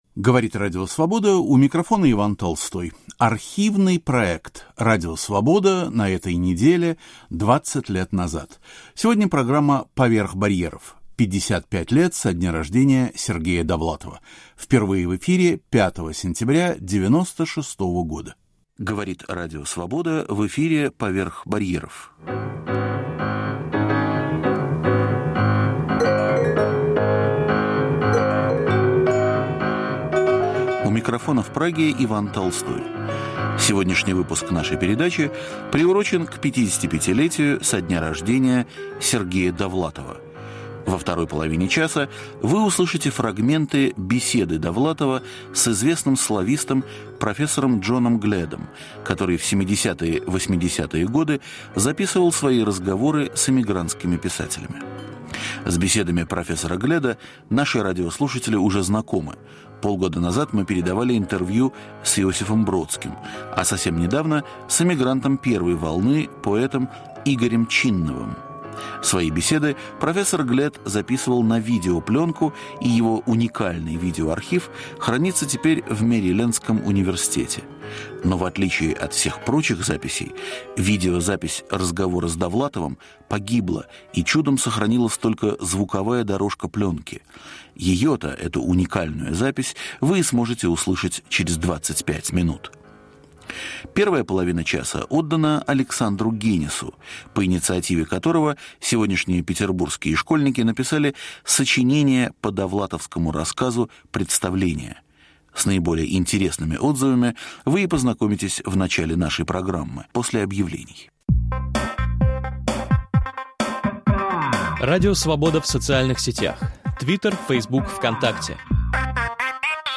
Передача из архива Радио Свобода к 55-летию со дня рождения Сергея Довлатова. Александр Генис публикует отрывки из школьных сочинений по рассказу Довлатова "Представление".